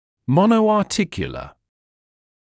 [ˌmɔnəuɑː’tɪkjulə][ˌмоноуаː’тикйулэ]моноартикулярный